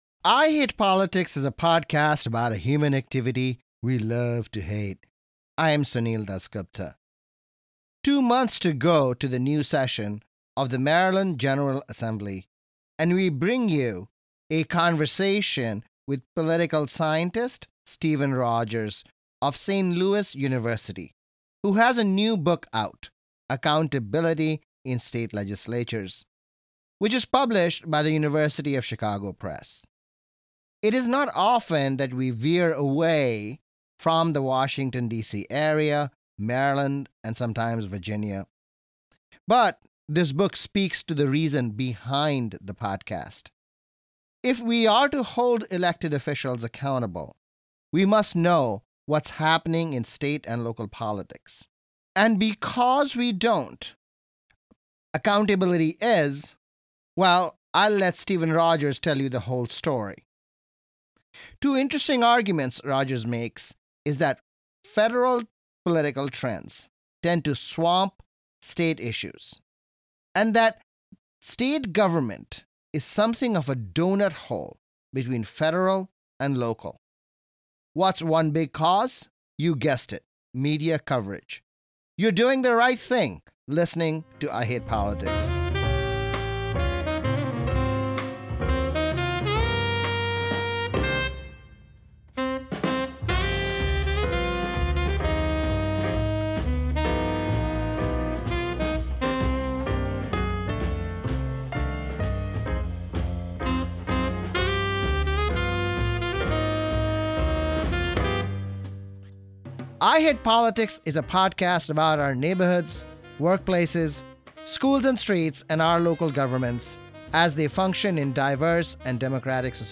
Local news about Rockville and Gaithersburg city elections and the continuing local fallout of the War in Gaza: immigrant rights group CASA missteps and a rally in Rockville is met with nasty rhetoric. Music